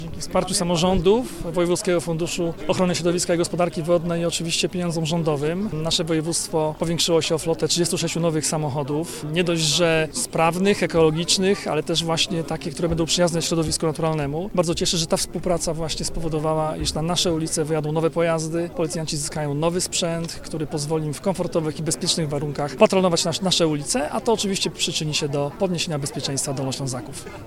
– Ta współpraca bardzo cieszy – przyznał Jarosław Rabczenko, członek zarządu województwa dolnośląskiego.